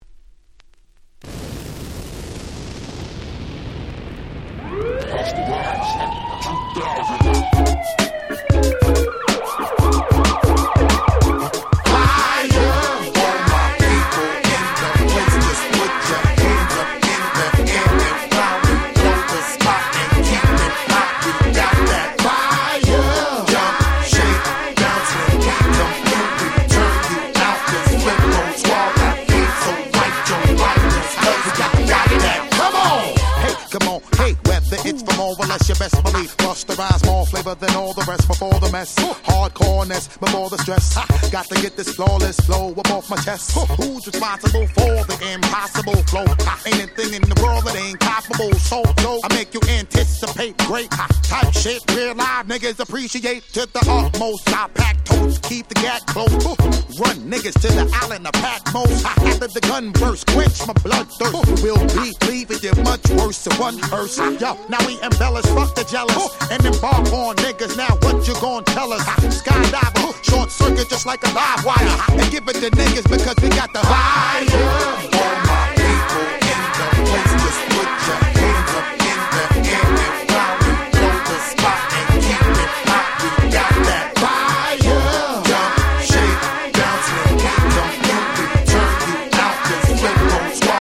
00' Smash Hit Hip Hop !!